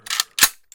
PLAY gun cocking
cocking.mp3